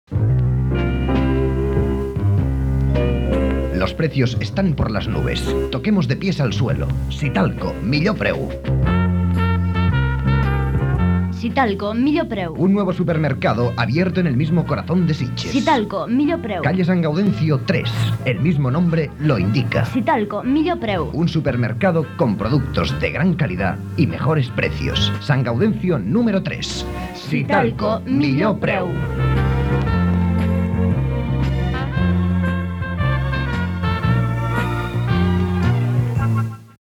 Anunci de Supermercat Sitalco.